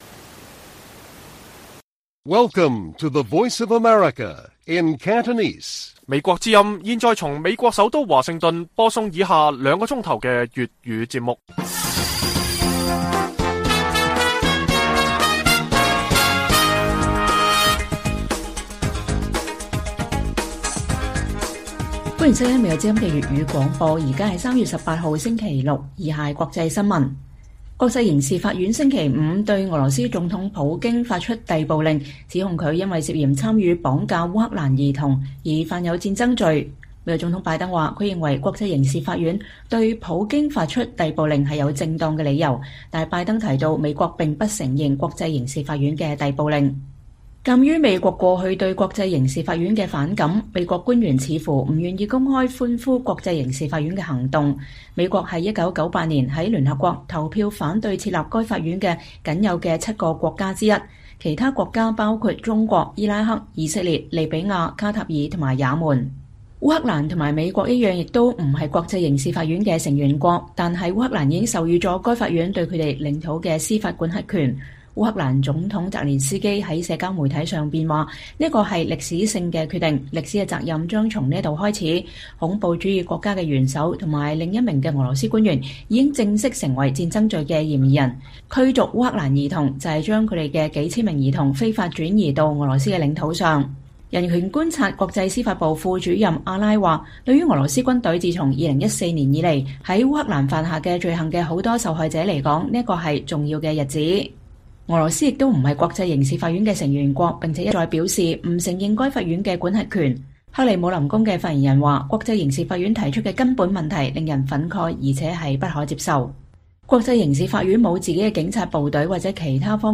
粵語新聞 晚上9-10點: 國際刑事法院對普京發出逮捕令